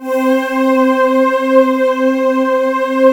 Index of /90_sSampleCDs/USB Soundscan vol.28 - Choir Acoustic & Synth [AKAI] 1CD/Partition D/23-SOMEVOICE